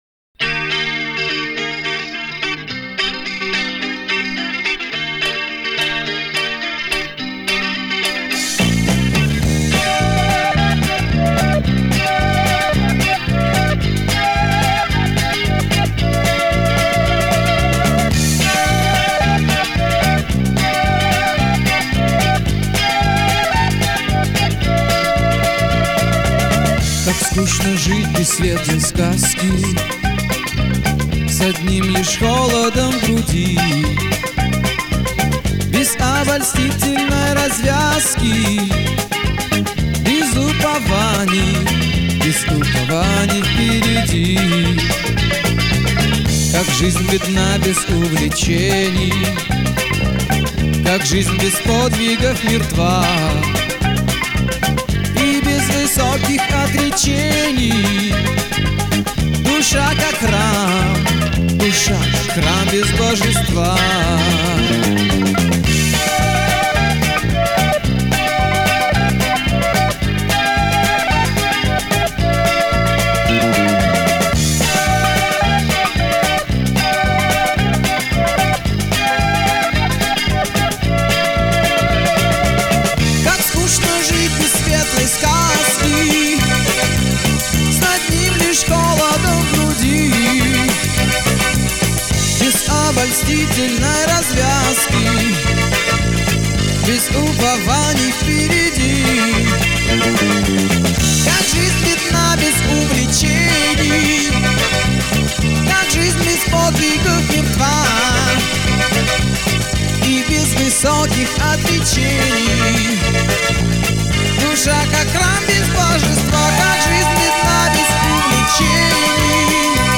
Жанр: Rock
Стиль: Hard Rock, Soft Rock, Pop Rock